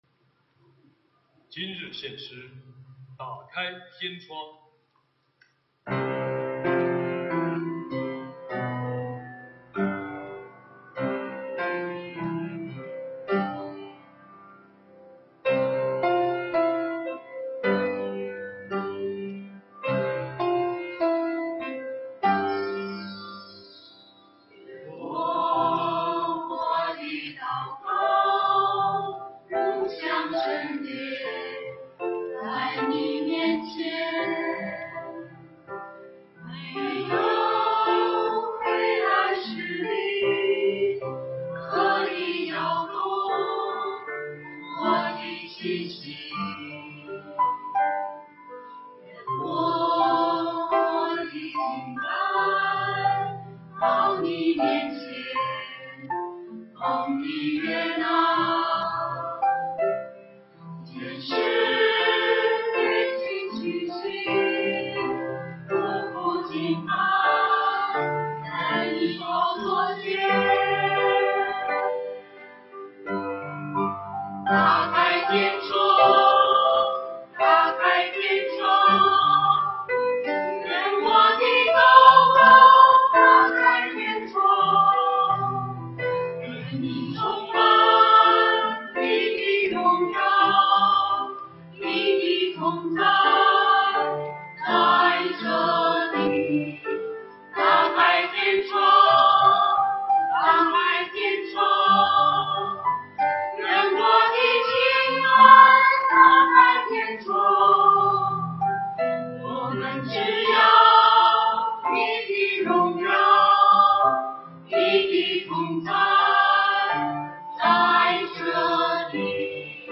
团契名称: 联合诗班 新闻分类: 诗班献诗 音频: 下载证道音频 (如果无法下载请右键点击链接选择"另存为") 视频: 下载此视频 (如果无法下载请右键点击链接选择"另存为")